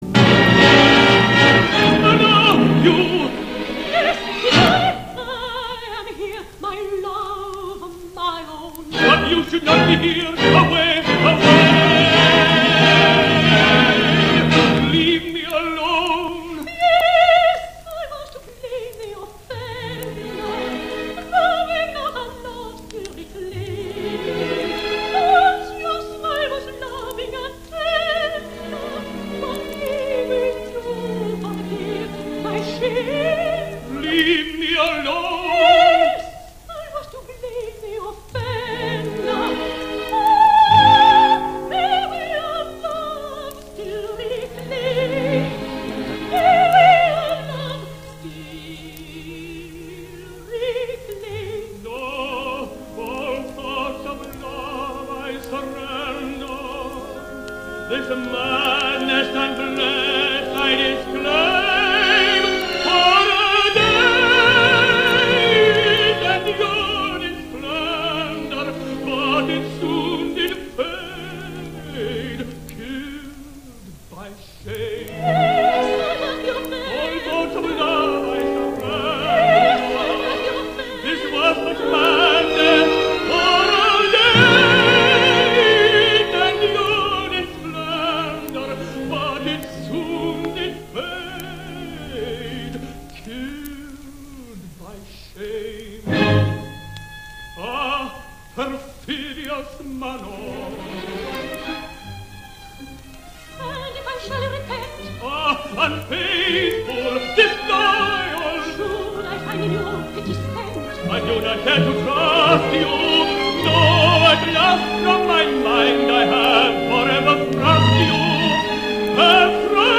una rappresentazione radiofonica
In questo caso il limite è triplice: Innanzitutto, l’età avanzata del soprano inglese che nella radiodiffusione si esibisce con una voce ancora salda e dal timbro giovanile, anche se leggermente fragile soprattutto in acuto.
la voce fin troppo leggera